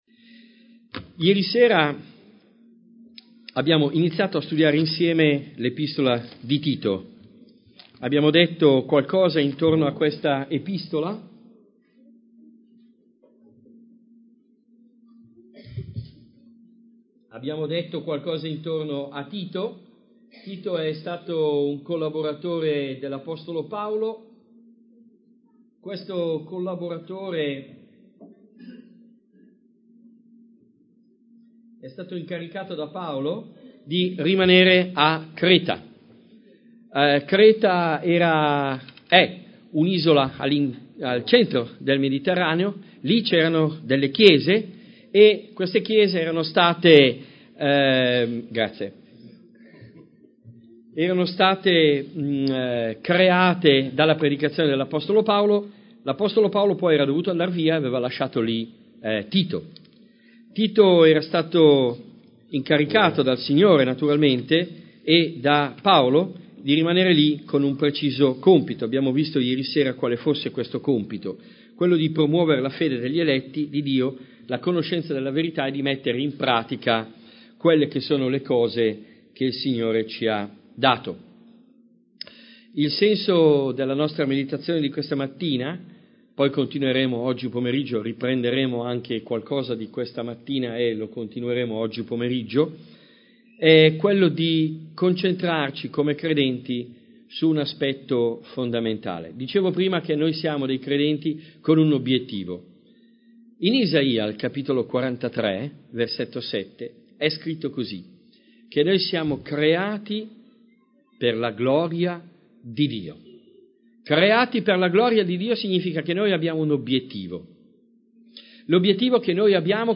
Tipo Di Incontro: Serie studi